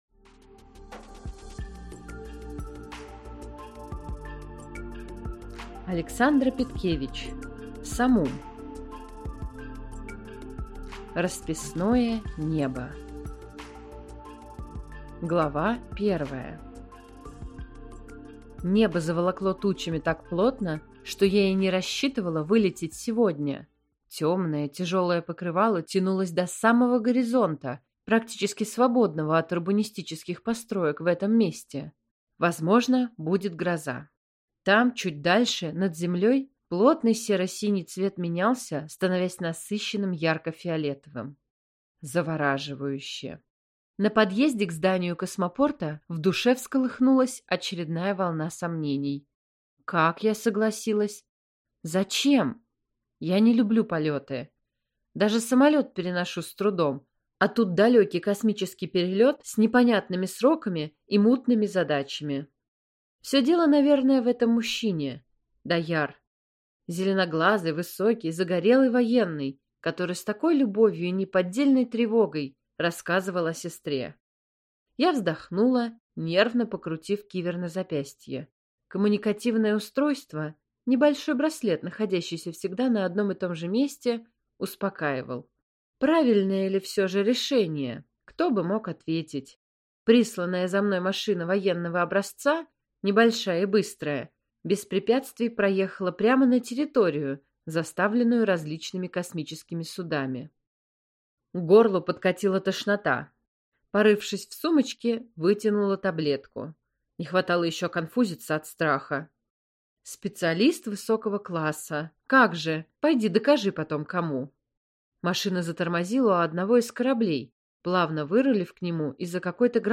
Аудиокнига Расписное небо | Библиотека аудиокниг